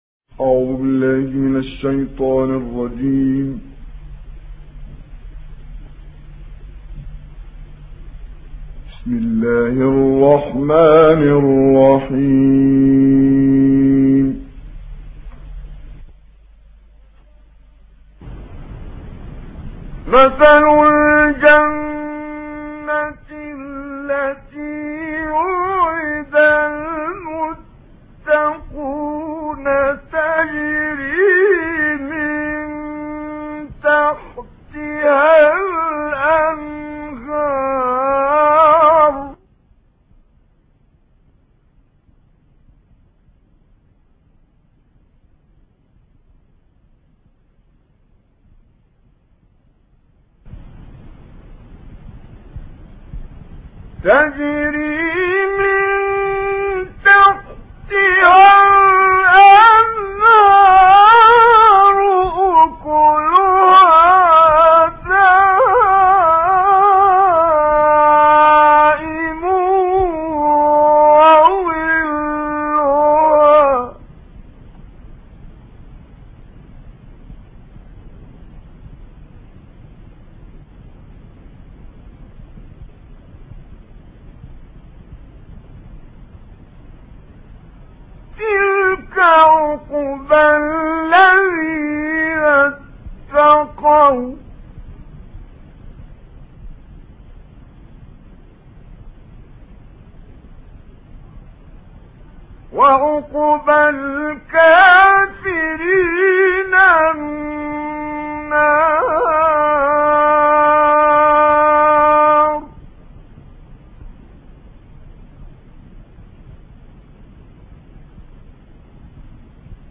Quran recitations
Reciter Kamel Yousf El Behteemy